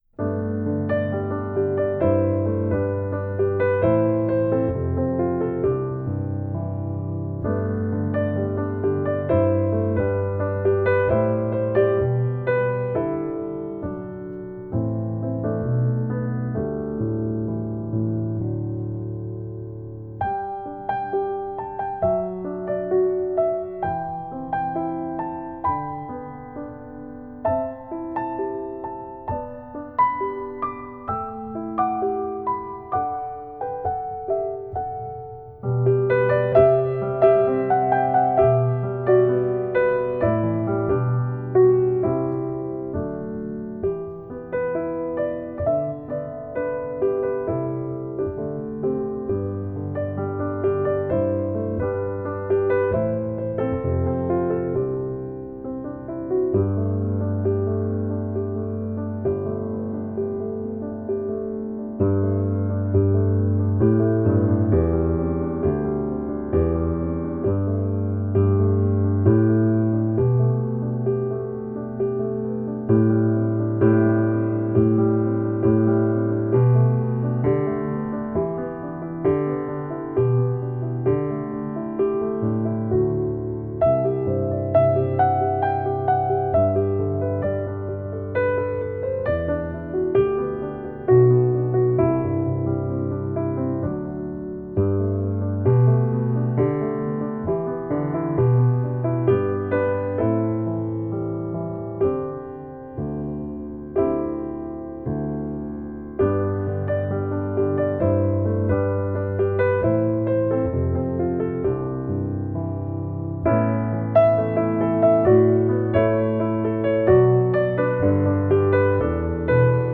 Voicing: Piano Collection